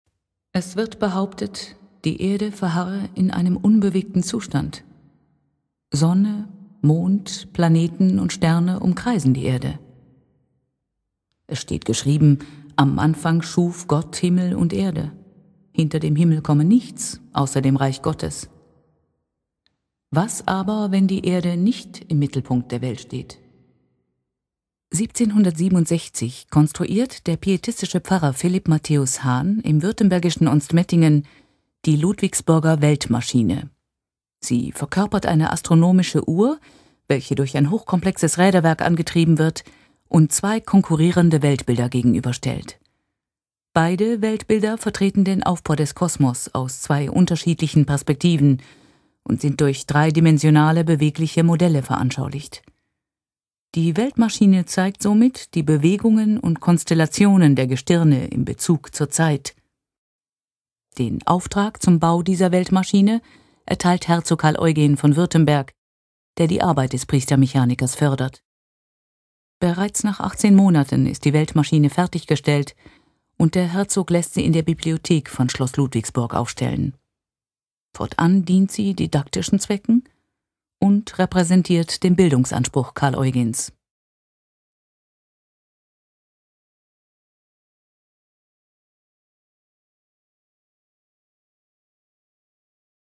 Sprechertext zur Computeranimation ludwigsburger Weltmaschine